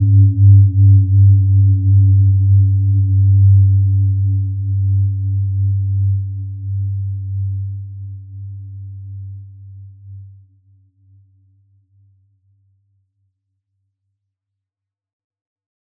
Gentle-Metallic-3-G2-mf.wav